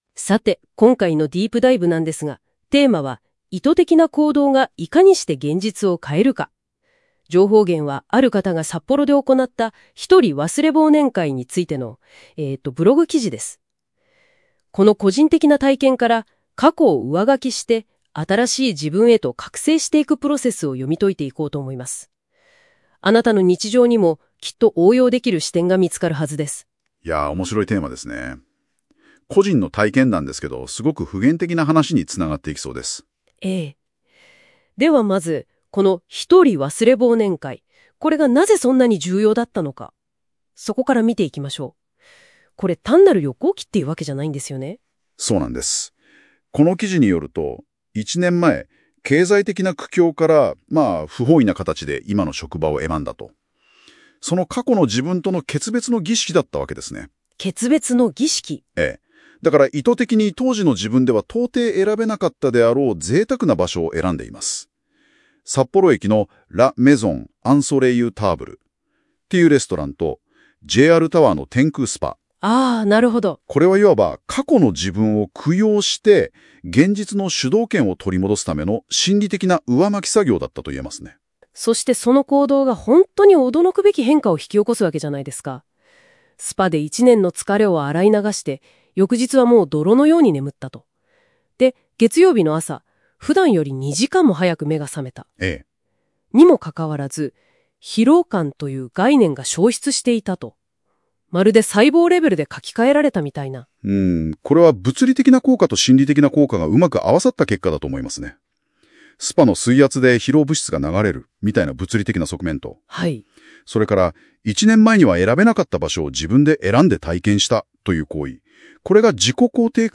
【音声解説】札幌ひとり忘年会が変えた現実